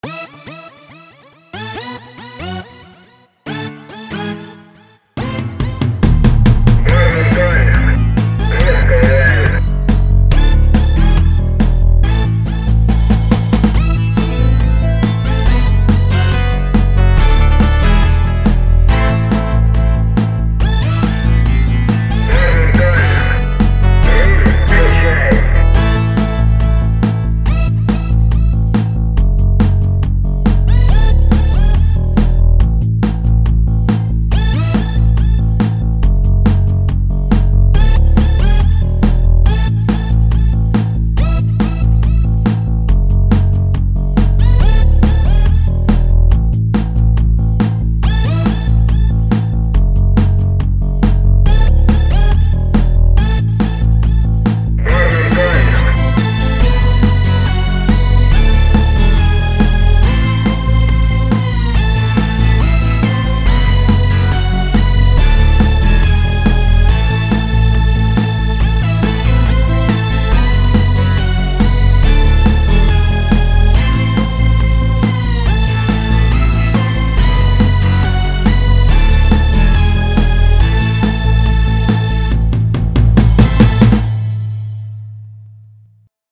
Кусок минусовки моей песни "Улетай".
Все собственного сочинения и исполнения.